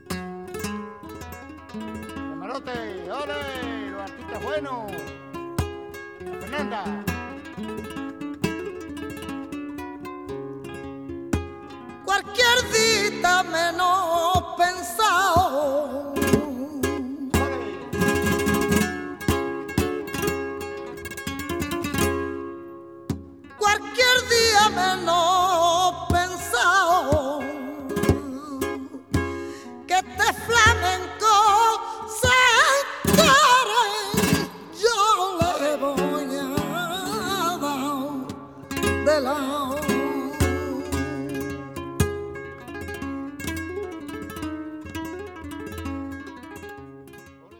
Soleá de Juaniquin / 2